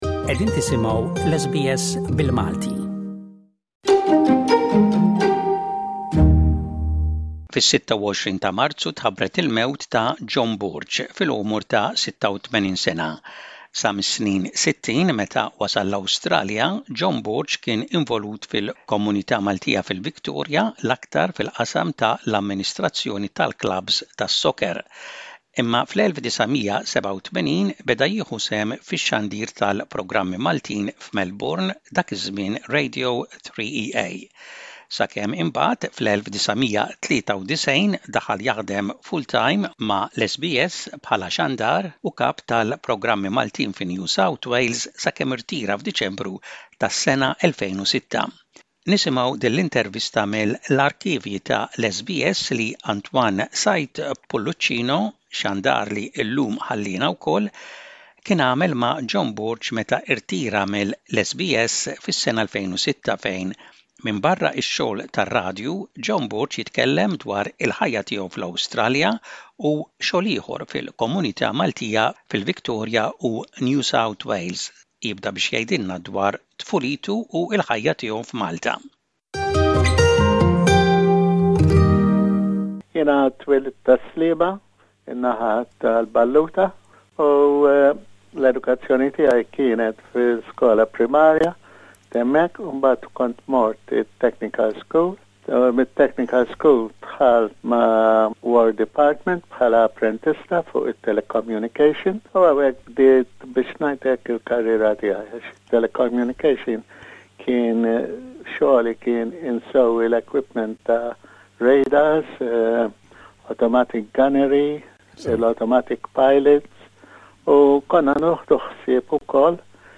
Intervista mill-2006